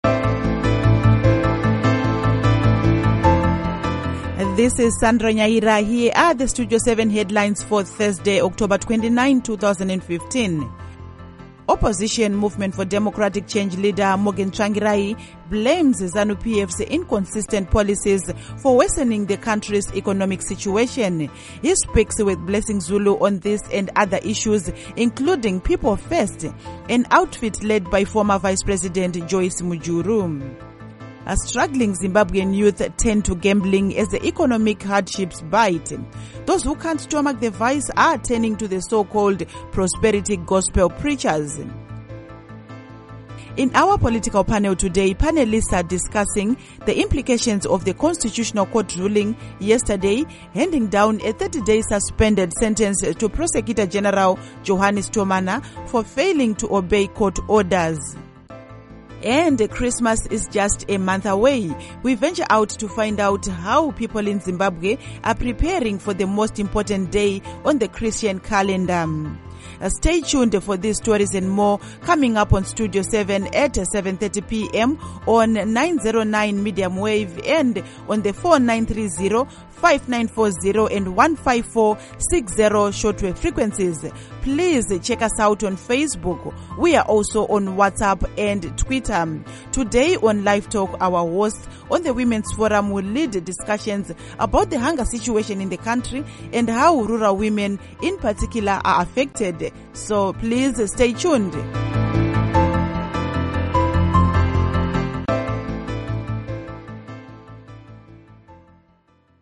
Studio 7 Headlines, Thursday, October 29, 2015